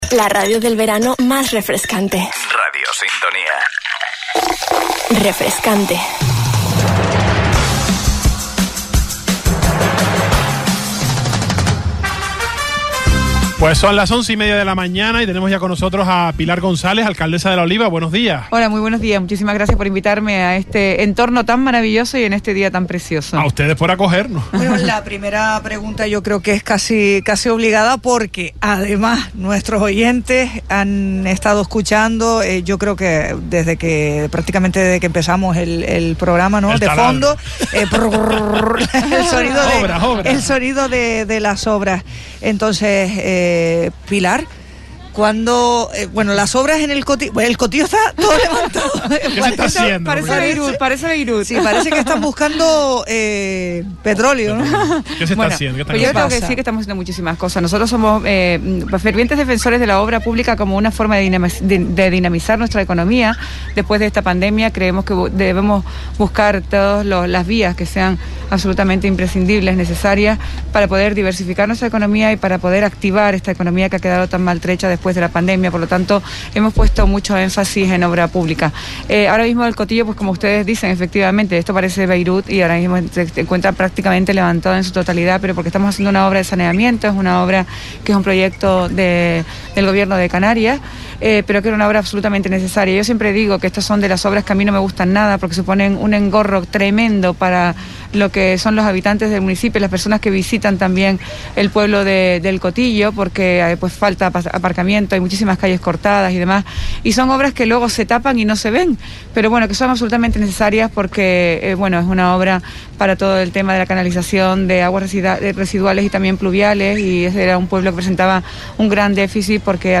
El Salpicón, directo desde El Cotillo, entrevista a Pilar González - 20.08.21 - Radio Sintonía